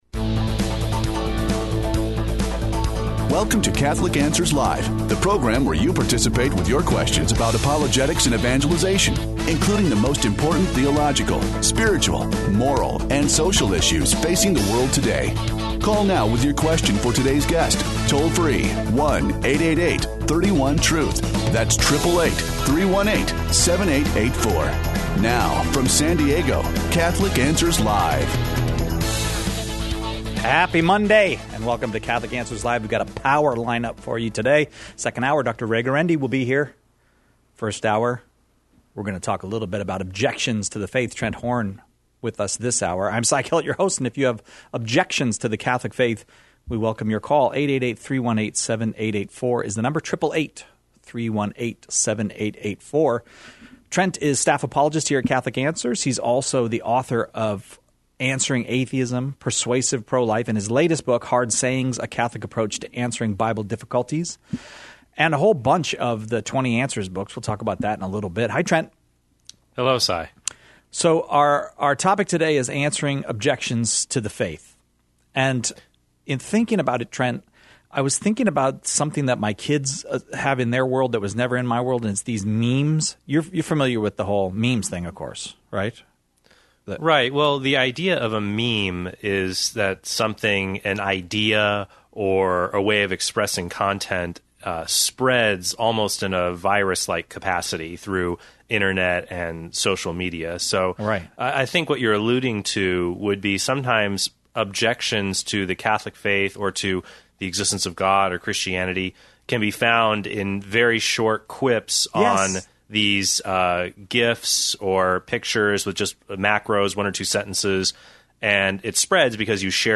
engages in dialogue with Protestants, Catholics, nones, and atheists.